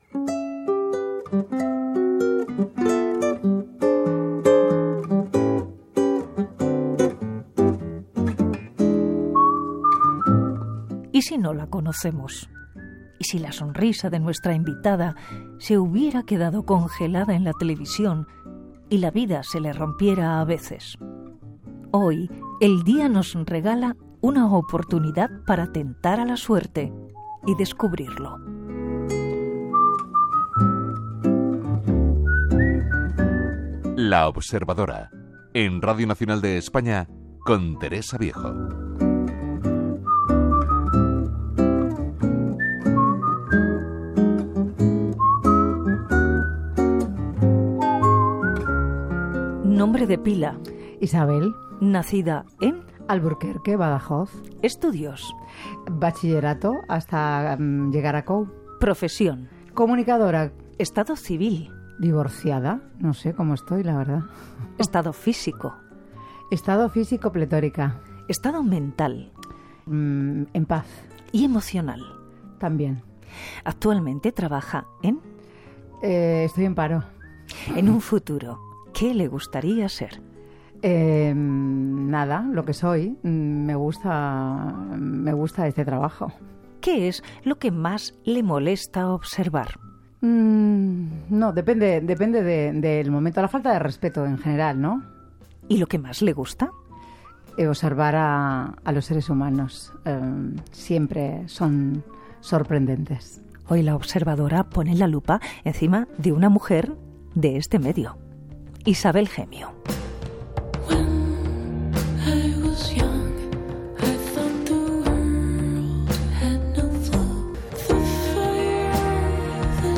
Introducció, indictaiu, fitxa de la invitada al programa, tema musical i entrevista a la presentadora Isabel Gemio que havia publicat el llibre "Mi hijo, mi maestro"